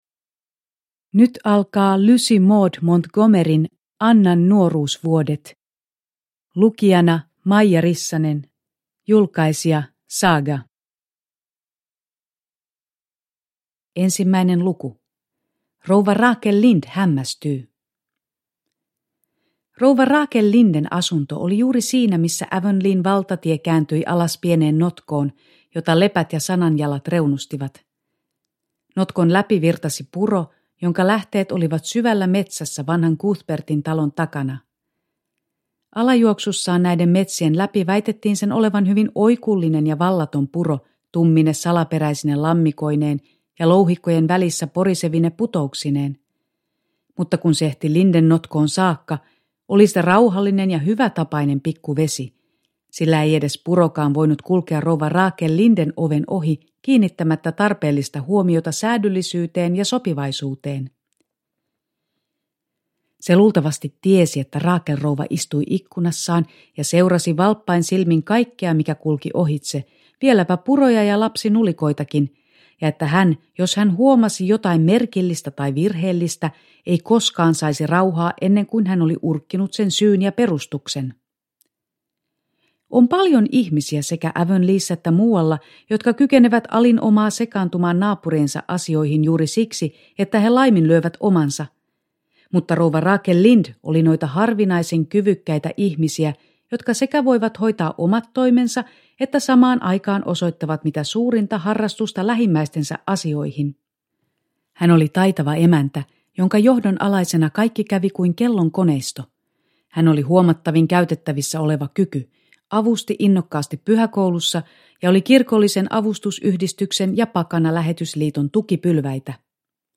Annan nuoruusvuodet – Ljudbok – Laddas ner